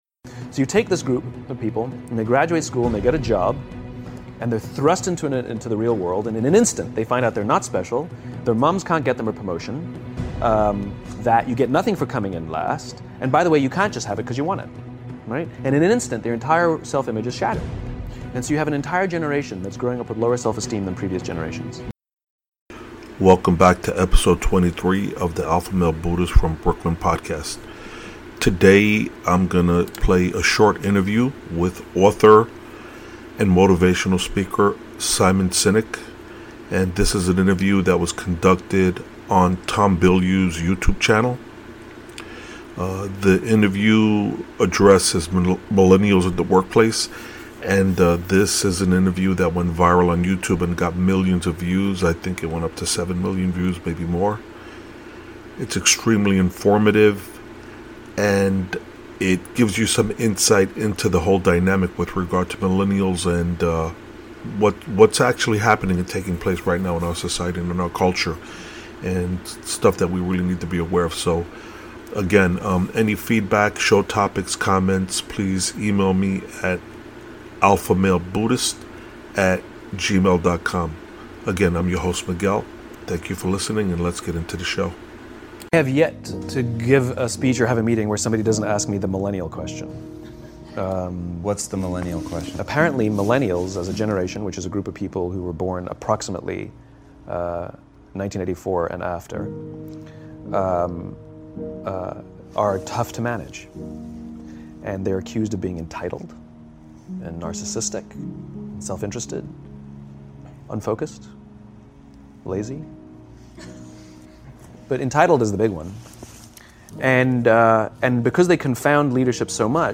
The following video is an excerpt from an episode of Inside Quest with Tom Bilyeu.